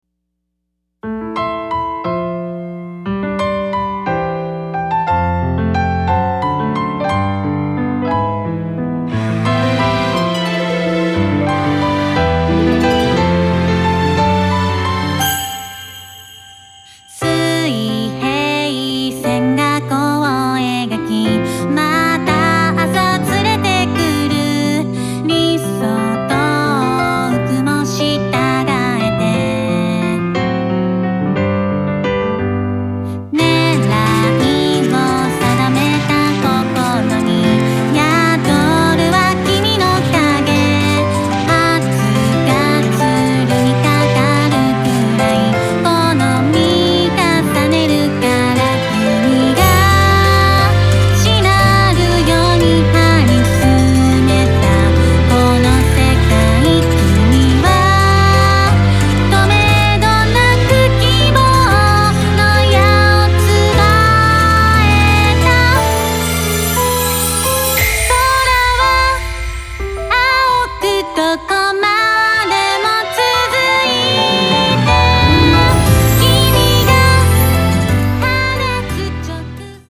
儚さポップ＆ロック全開！
Guitar
Bass
Piano & strings